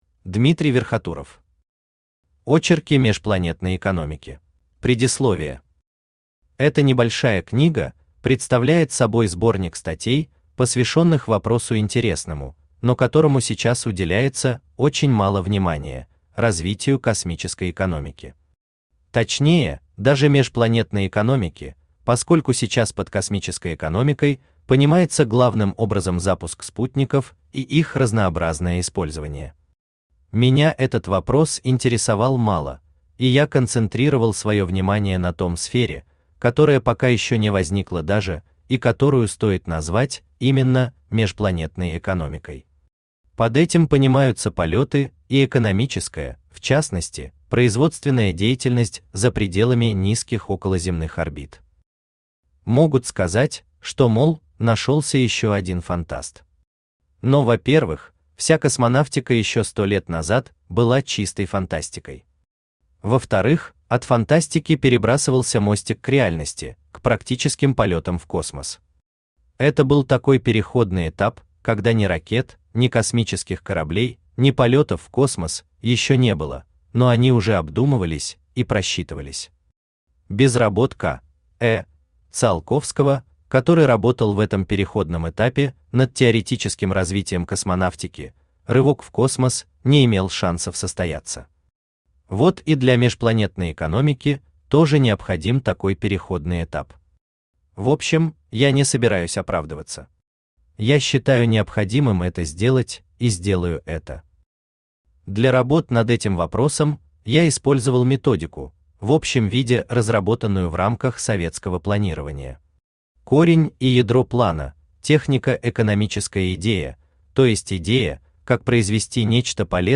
Аудиокнига Очерки межпланетной экономики | Библиотека аудиокниг
Aудиокнига Очерки межпланетной экономики Автор Дмитрий Николаевич Верхотуров Читает аудиокнигу Авточтец ЛитРес.